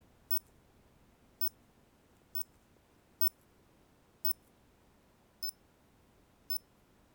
Звуки градусника
Звук замера температуры градусником (писк)